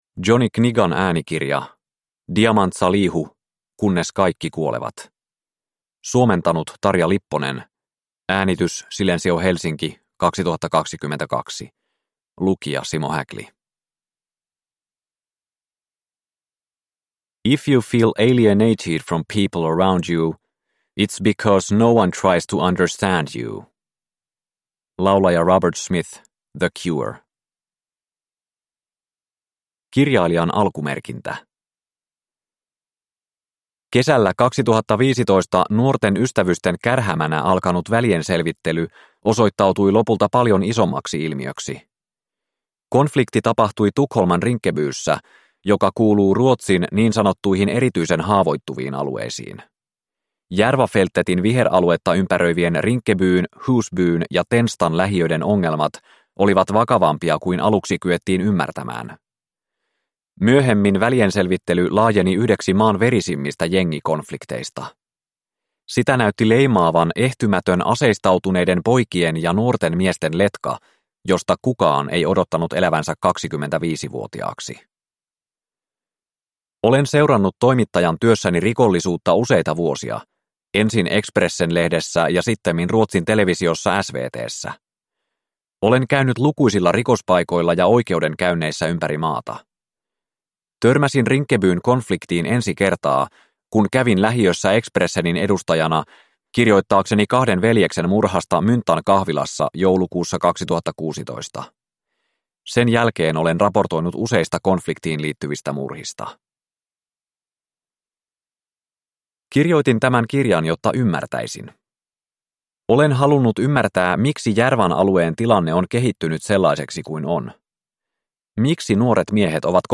Kunnes kaikki kuolevat (ljudbok) av Diamant Salihu